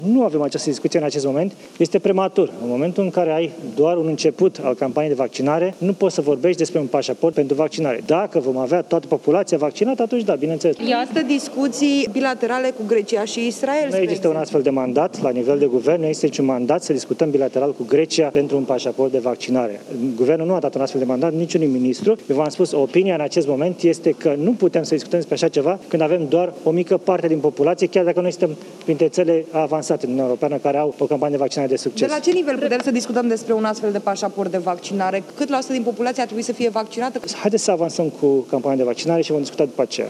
Premierul a fost întrebat dacă a avut o discuție cu președintele Iohannis pe această temă: